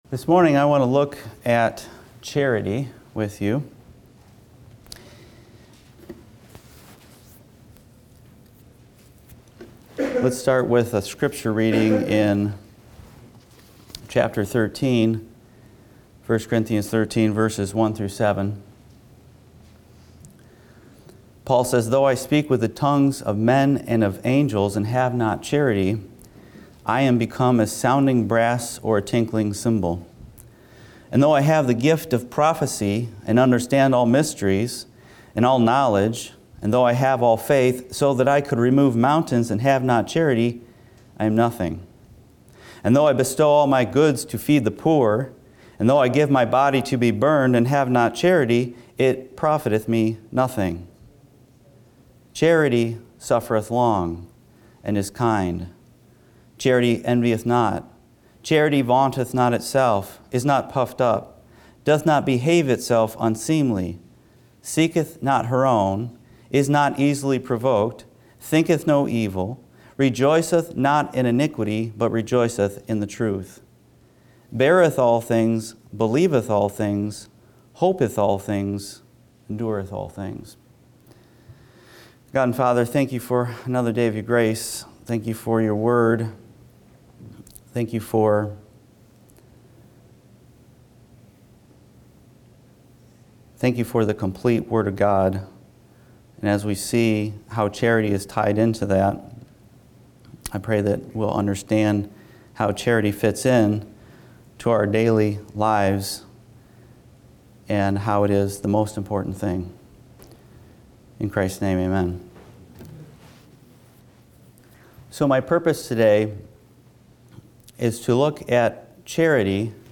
Sermons & Single Studies / Sunday 11am